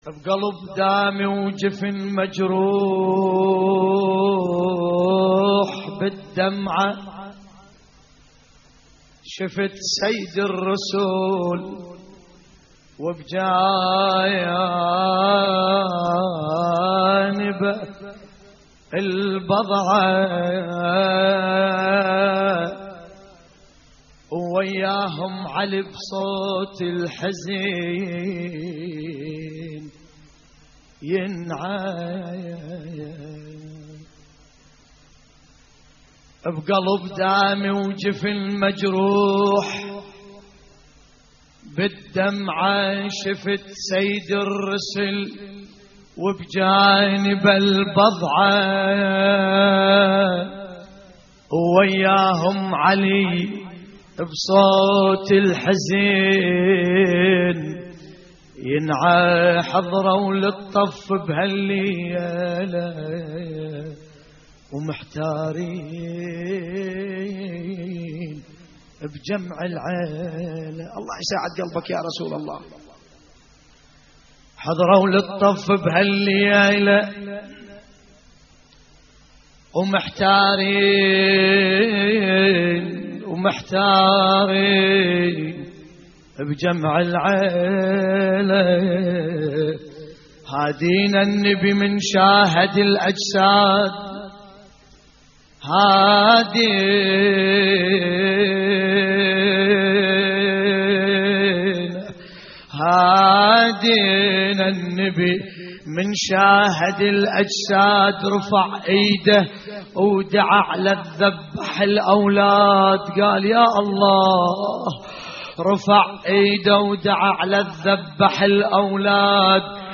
نعي لحفظ الملف في مجلد خاص اضغط بالزر الأيمن هنا ثم اختر